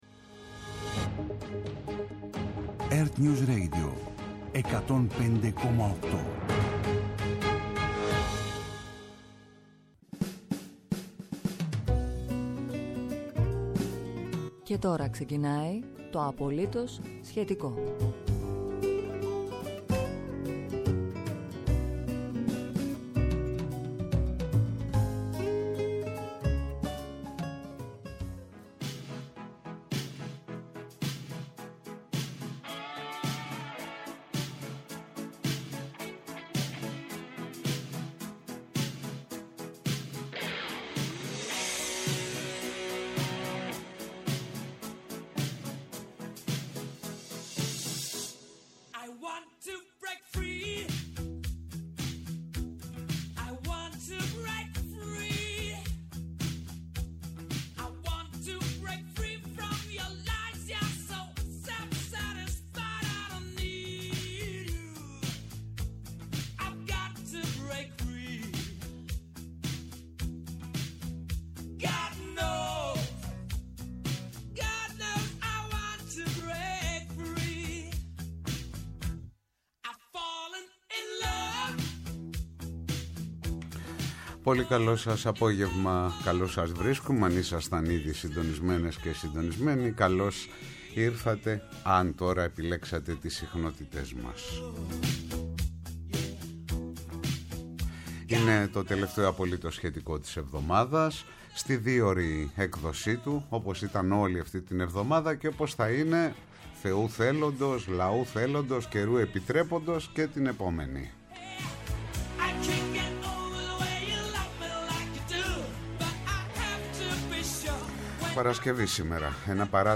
δημοσιογράφος αναλυτής για τον πόλεμο Πακιστάν- Αφγανιστάν